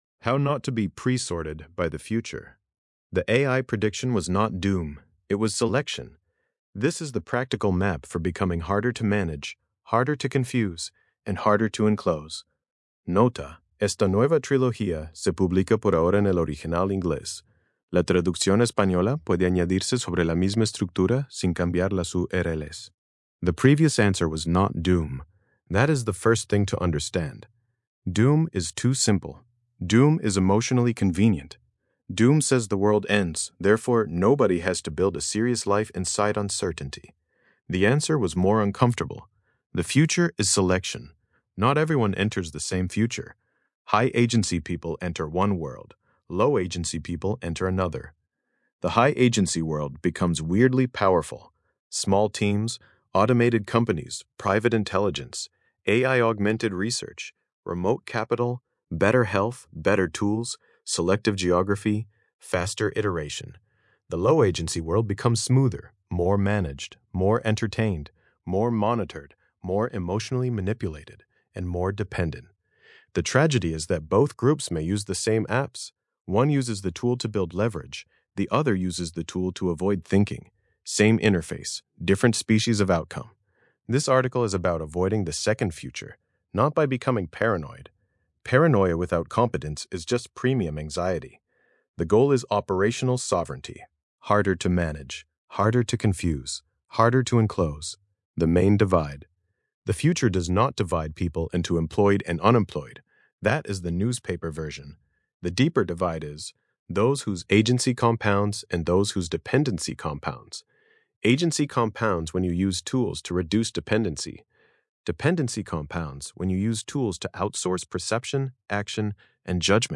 Versión de audio estilo podcast de este ensayo, generada con la API de voz de Grok.